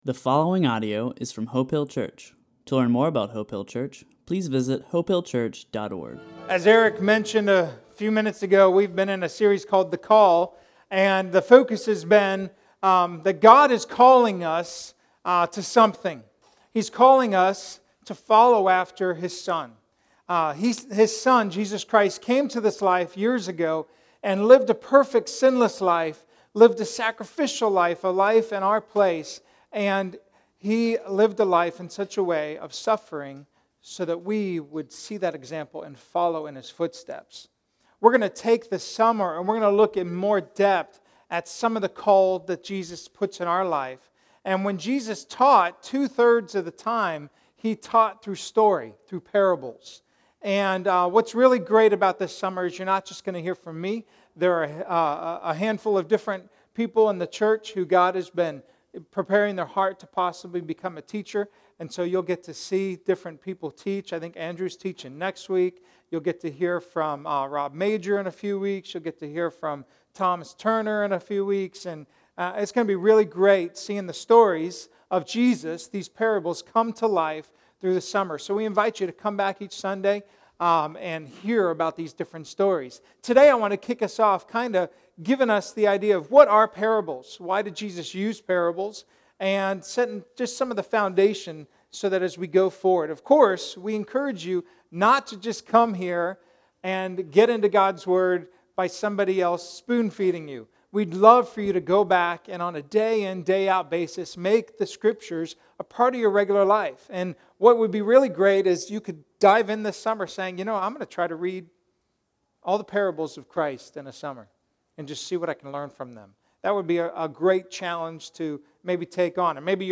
A message from the series "The Bible."